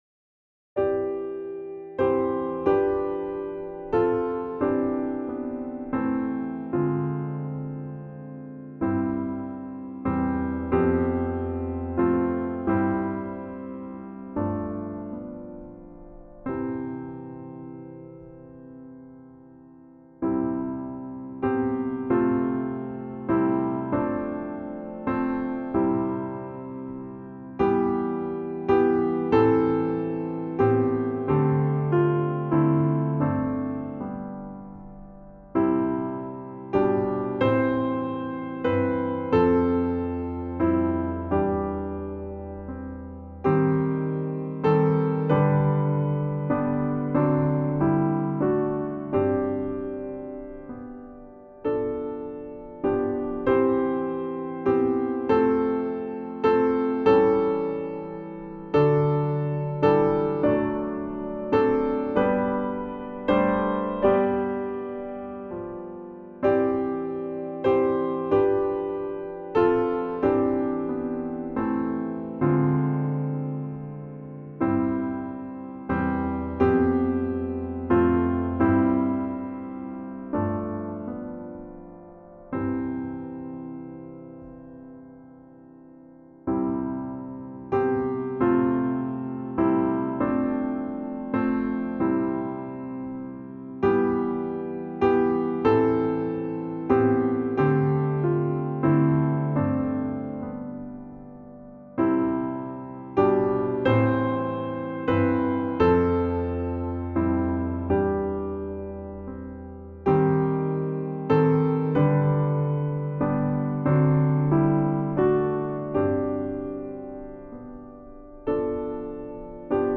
Voicing/Instrumentation: SATB
Very nice harmonies! 5.0 stars.